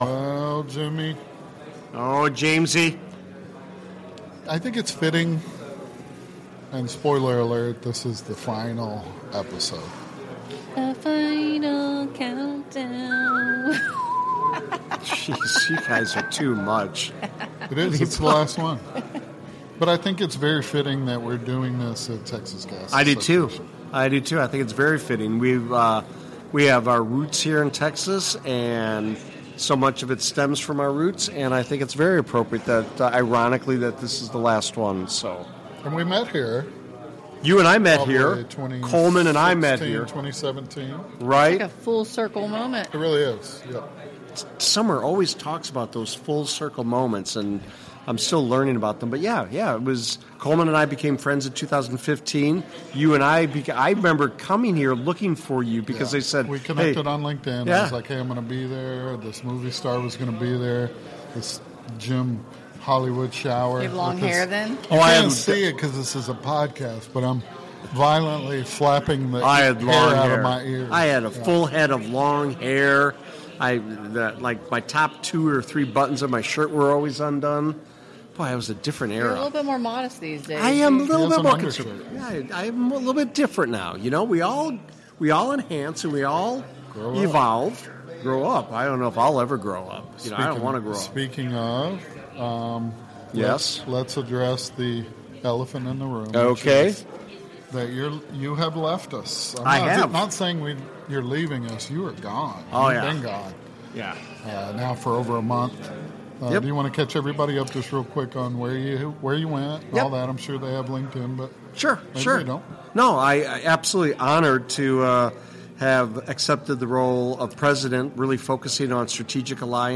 Live @ Texas Gas Association for a Final Goodbye